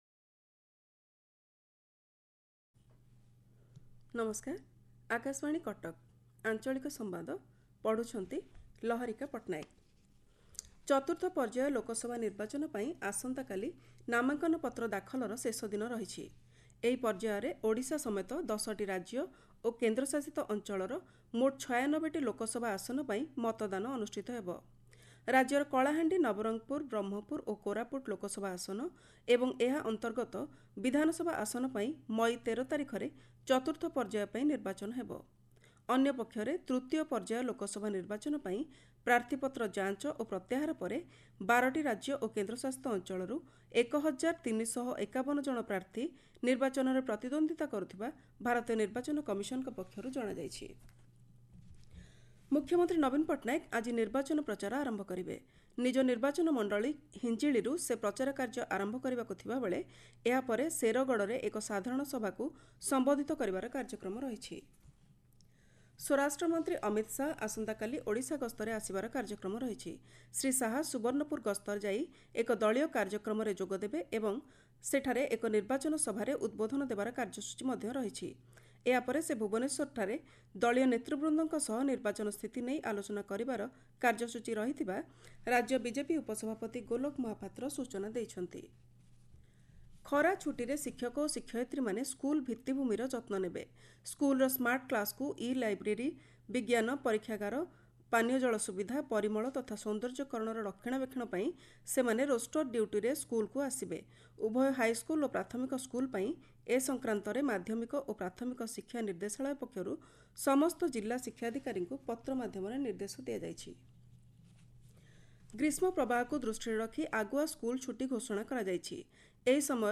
AIR-NEWS-CUTTACK.mp3